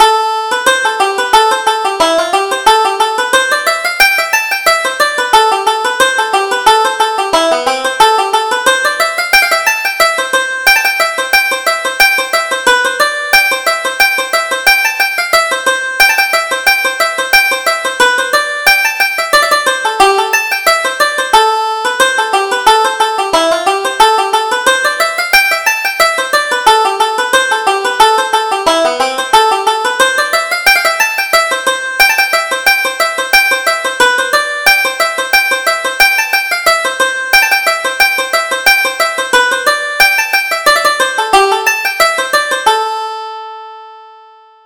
Reel: The Leitrim Thrush